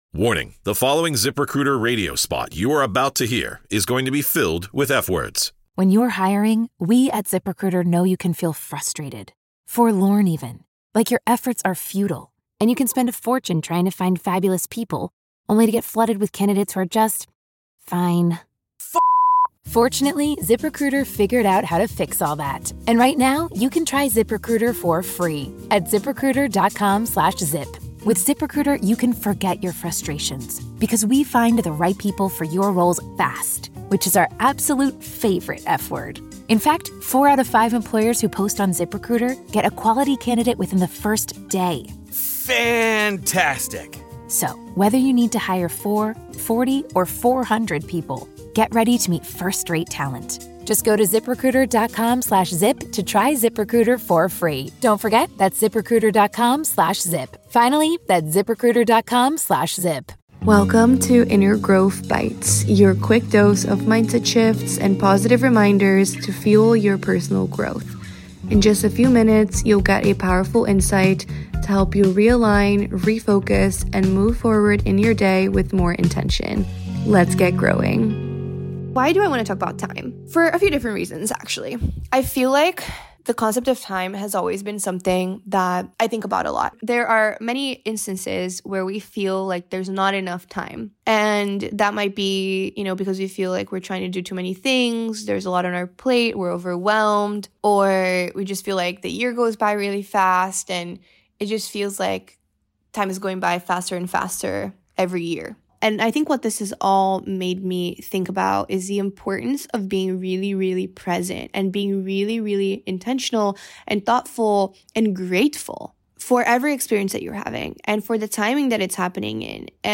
Here to fuel your personal evolution one podcast at a time, Inner Growth provides fun, real & deep interviews + conversations that strengthen your connection to self, elevate your mindset and help you find more self love.